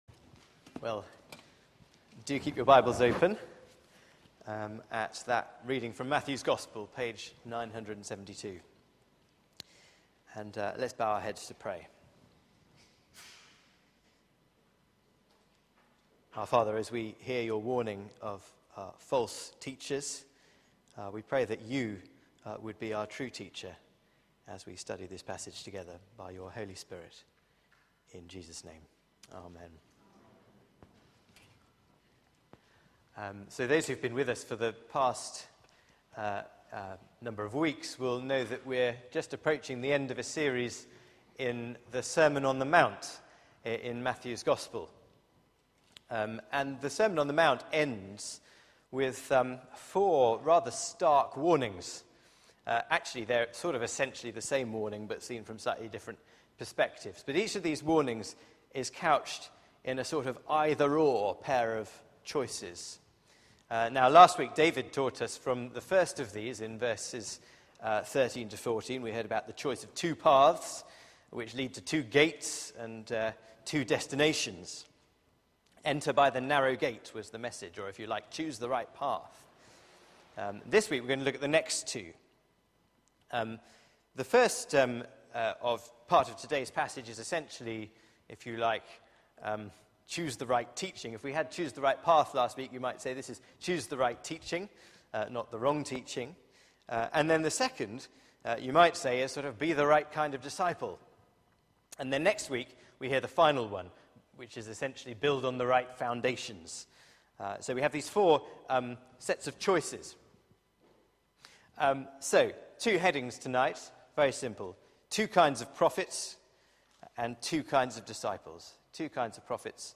Media for 6:30pm Service on Sun 14th Jul 2013
The Masterclass: The Sermon on the Mount Theme: By their fruit